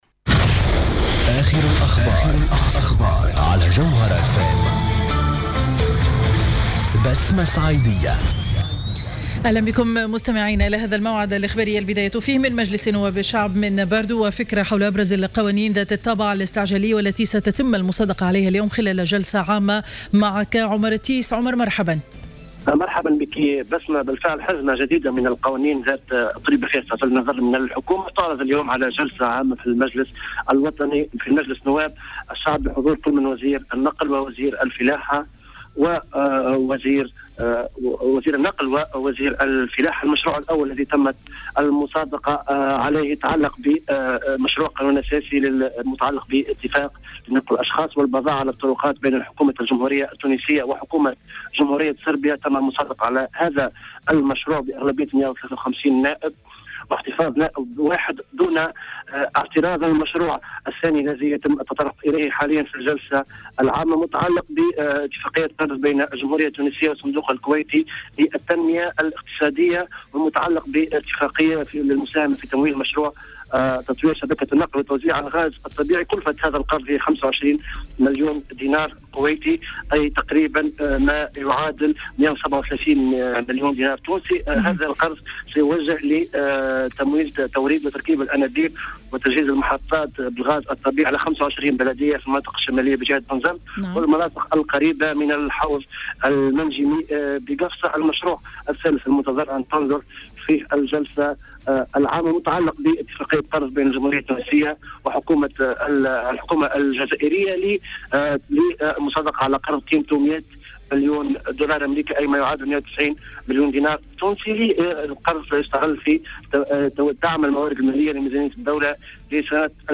نشرة أخبار منتصف النهار ليوم الثلاثاء 05 ماي 2015